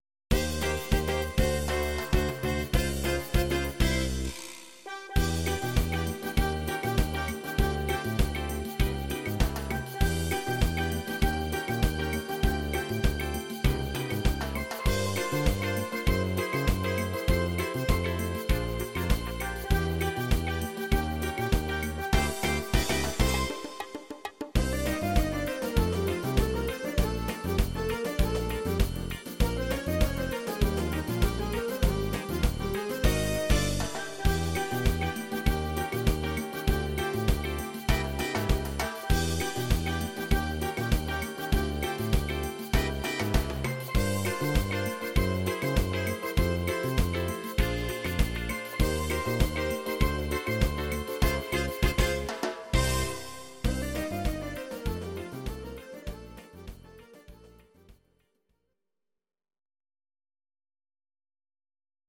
Bar Piano